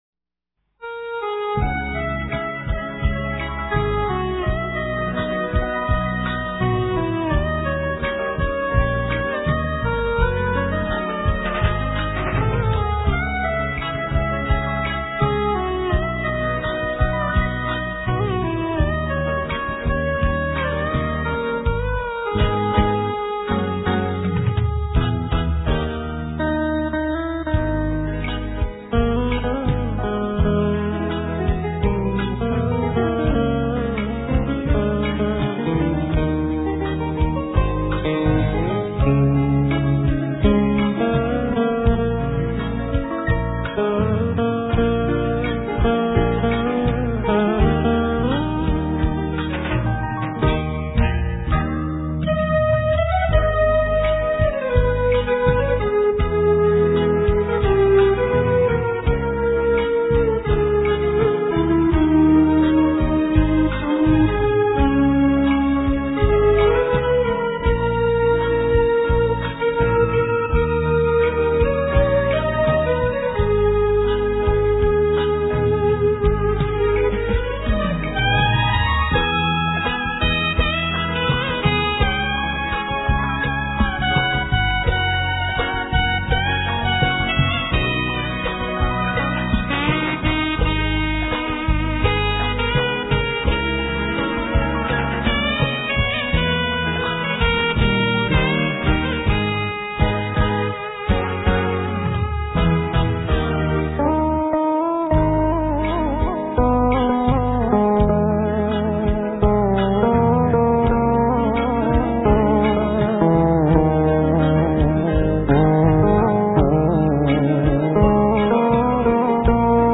* Thể loại: Việt Nam